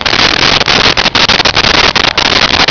Sfx Surface Branches Loop
sfx_surface_branches_loop.wav